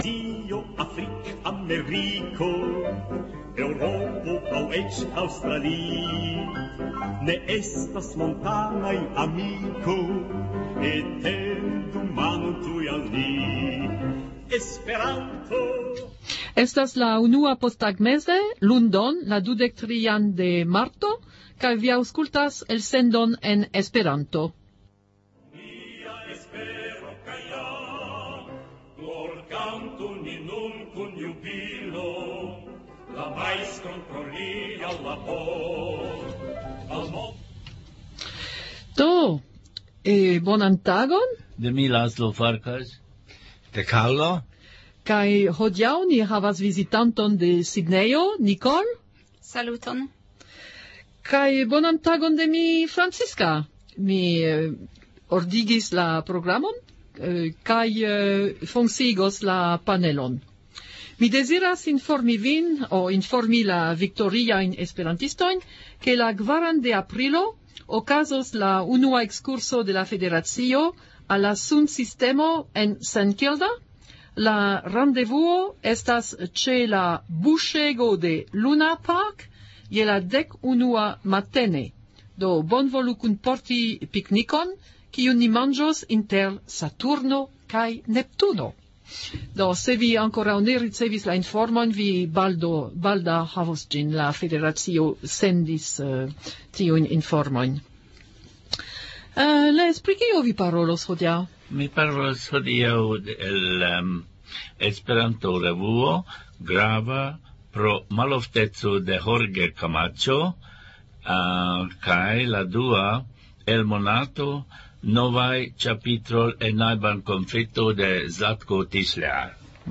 Kanto : « La dek ses tunoj » kantita de Claude Piron